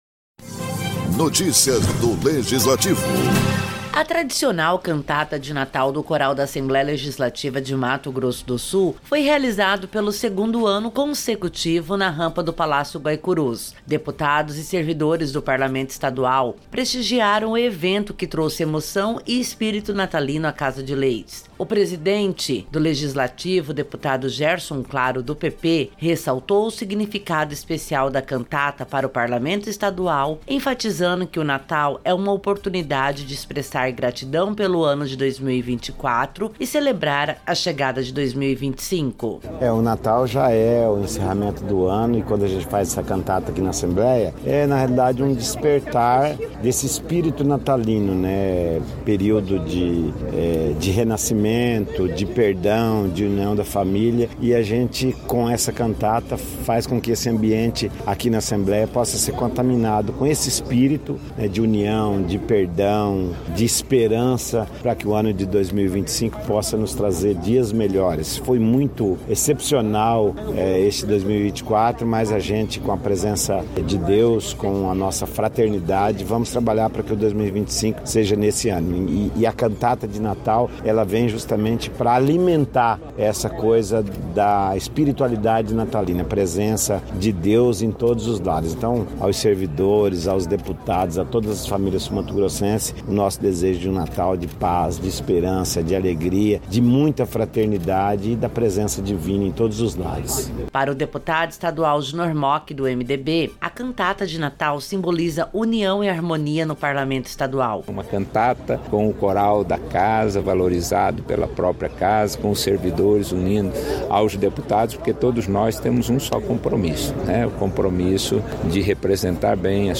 A Assembleia Legislativa de Mato Grosso do Sul (ALEMS) realizou a tradicional Cantata de Natal, com a participação dos deputados estaduais, servidores do Poder Legislativo, a população no geral e apresentações do Coral da Casa de Leis, na rampa do Palácio Guaicurus.